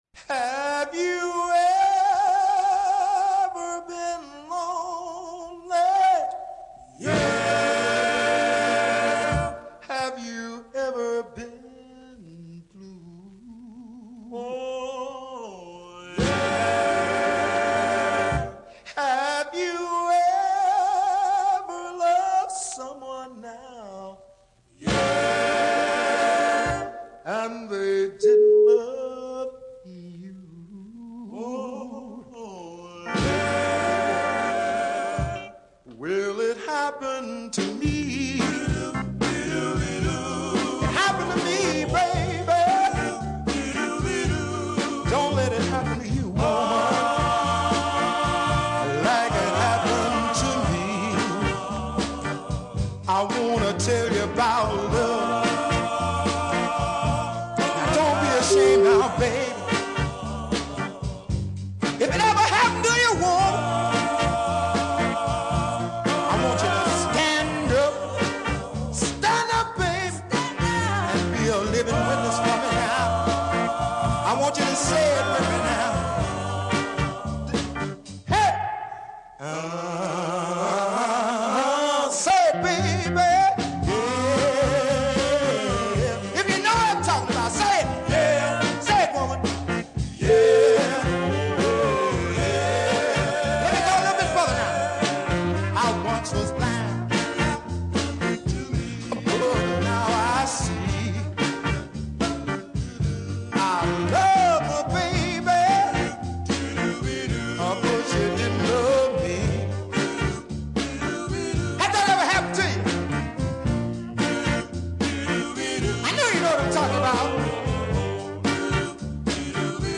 excellent two part
righteous gospel “call and response” passage
backing vocalists
a very churchy toe tapper